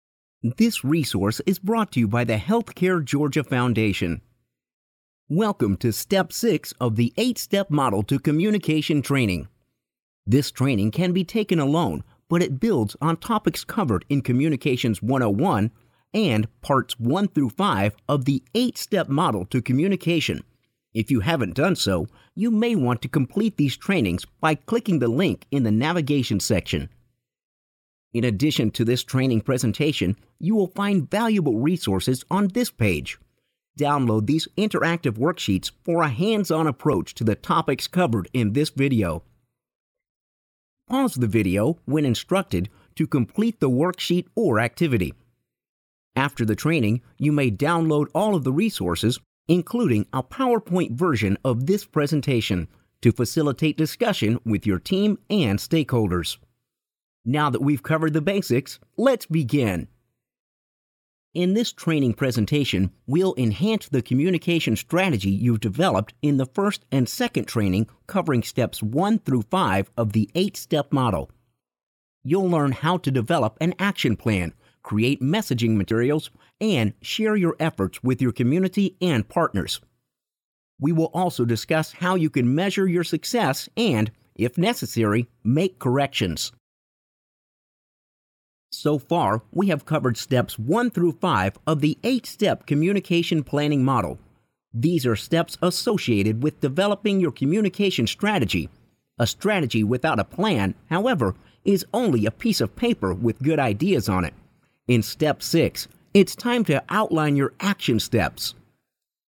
Male
E-Learning